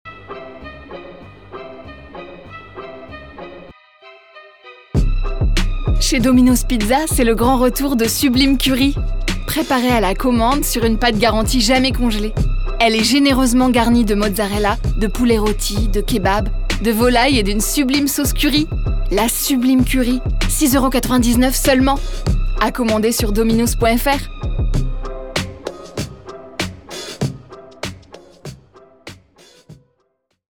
pub 2
Voix off
6 - 40 ans - Soprano